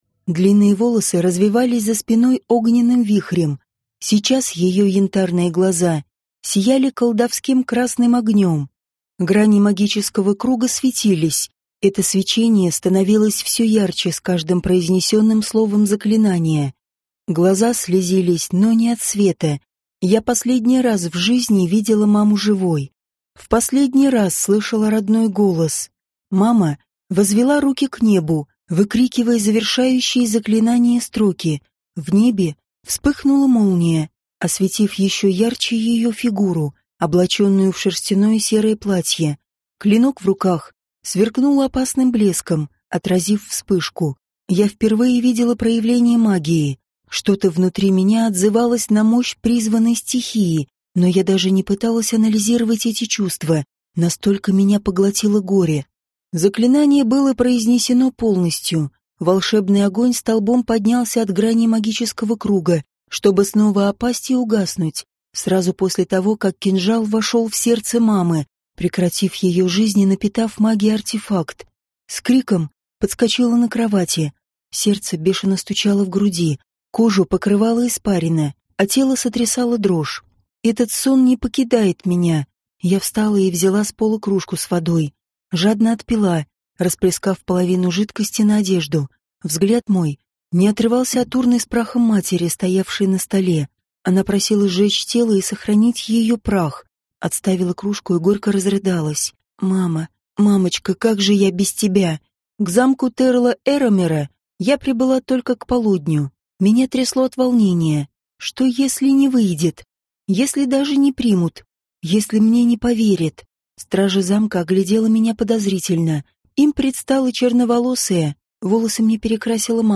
Аудиокнига Восстающая из пепла | Библиотека аудиокниг
Прослушать и бесплатно скачать фрагмент аудиокниги